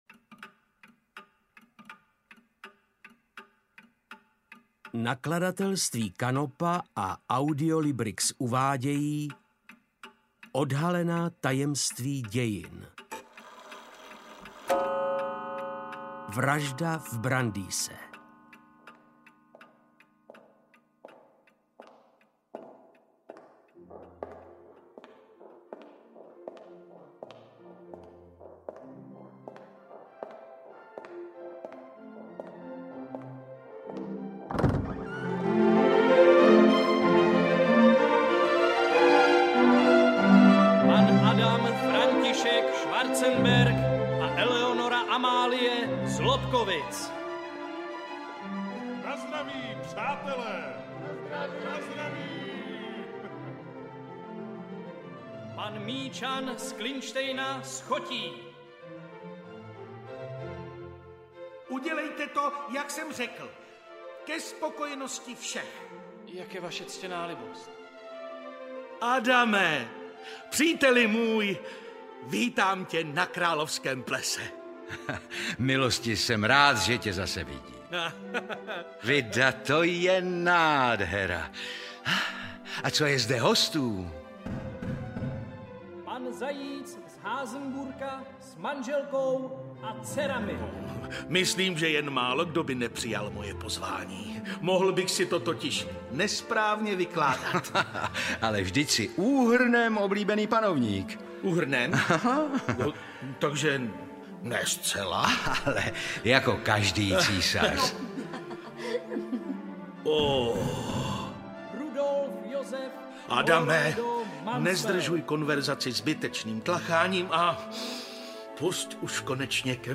Vražda v Brandýse audiokniha
Ukázka z knihy